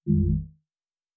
pgs/Assets/Audio/Sci-Fi Sounds/Interface/Error 19.wav at master
Error 19.wav